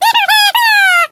squeak_die_vo_02.ogg